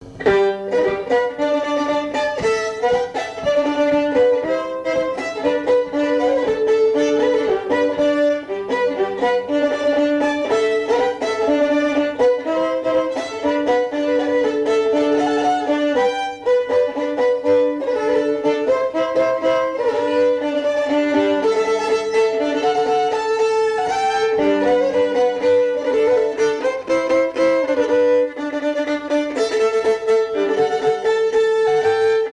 Mémoires et Patrimoines vivants - RaddO est une base de données d'archives iconographiques et sonores.
danse : polka
Pièce musicale inédite